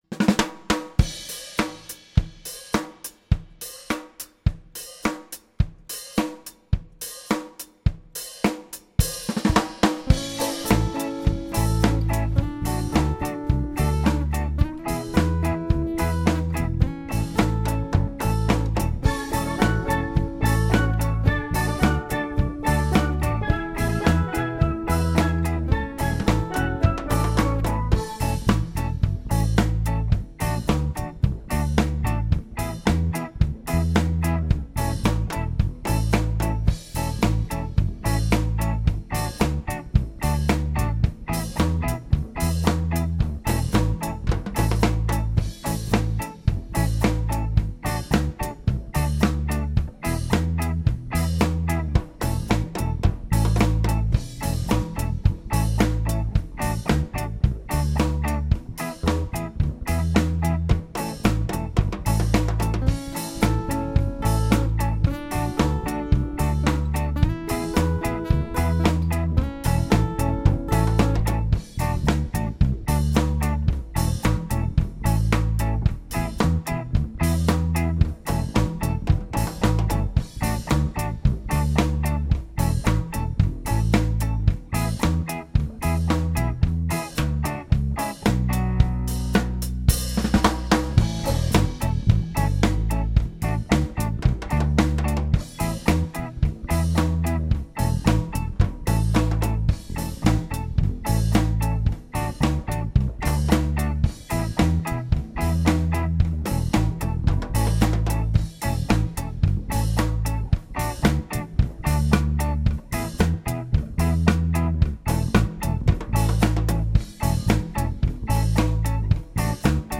vocals
guitar
drums except on Scillies
Recorded in Studio PH14 ASE on Texel.